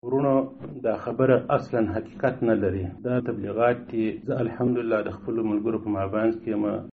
پیام صوتی: ملا اختر محمد منصور میگوید، وی جور و صحتمند است